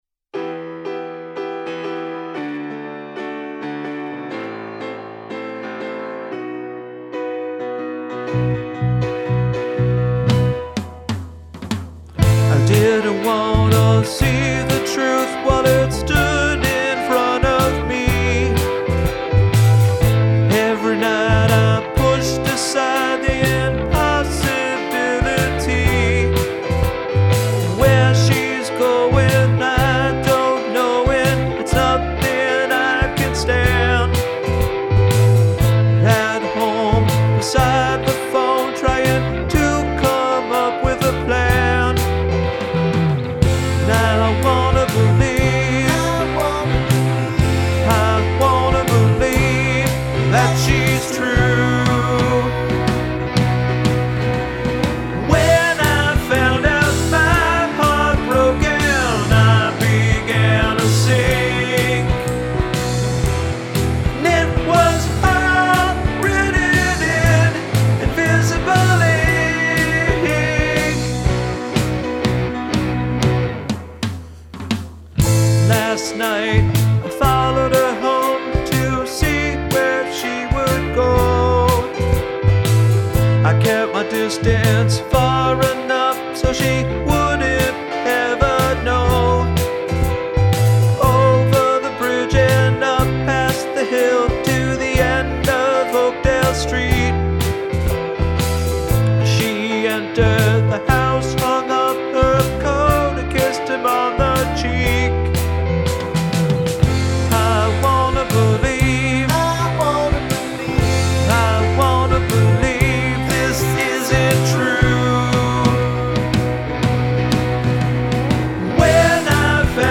Bass, Lead Guitar
Piano, Organ
Drums, Vocals